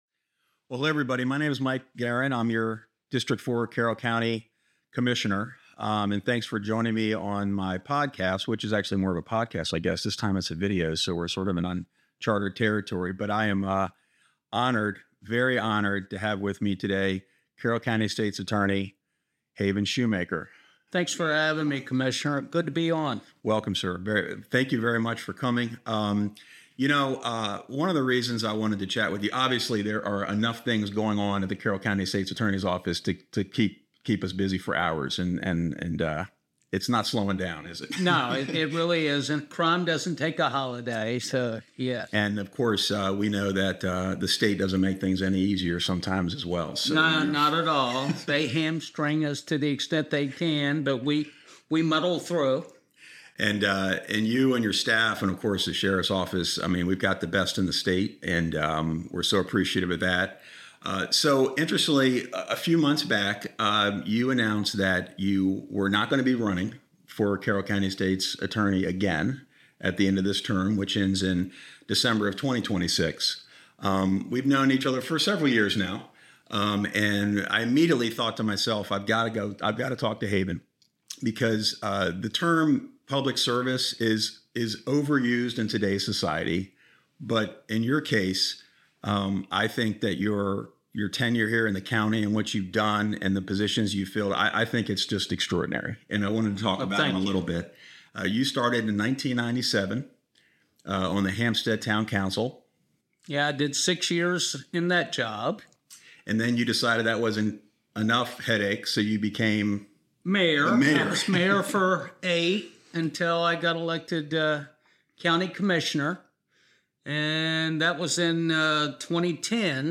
Commissioner Guerin's 2026 State Of The County Address (MP3) ~ 9409 Kb